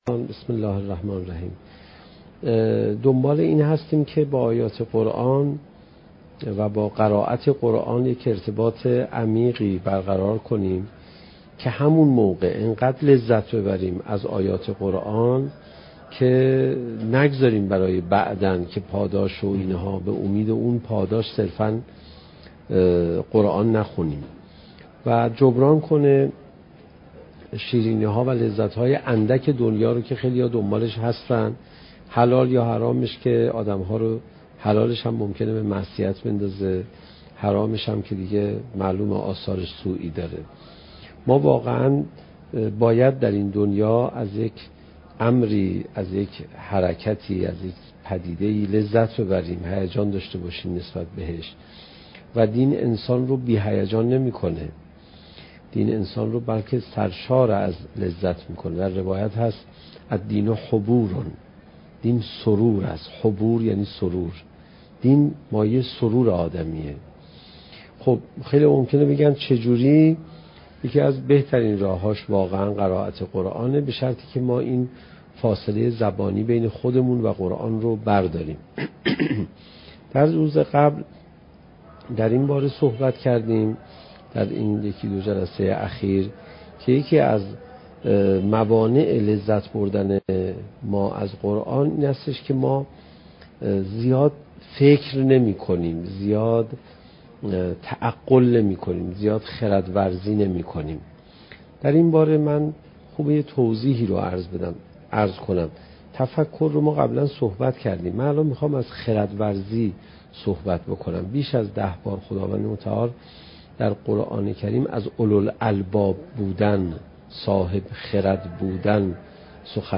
سخنرانی حجت الاسلام علیرضا پناهیان با موضوع "چگونه بهتر قرآن بخوانیم؟"؛ جلسه بیست دوم: "جایگاه خردورزی در دین"